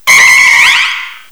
cry_not_mega_audino.aif